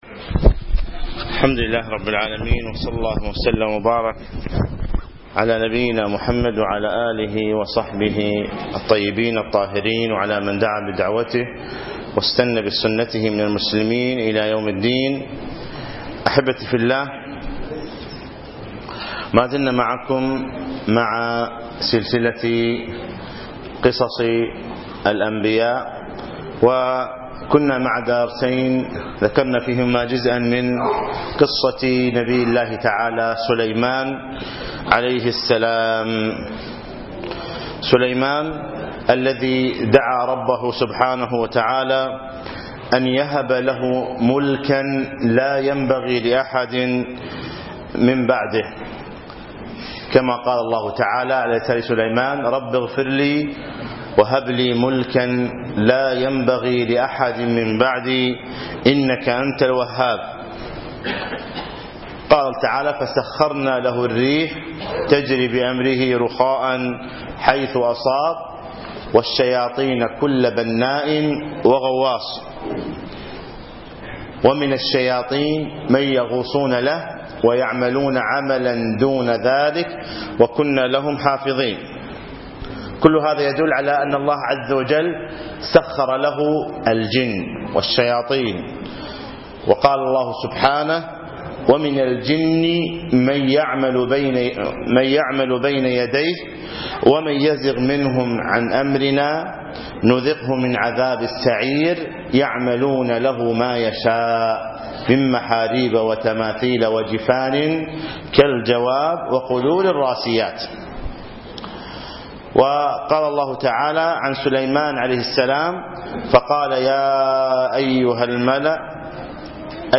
يوم الجمعة 3 4 2015 بعد صلاة المغرب في مسجد عطارد بن حاجب الفروانية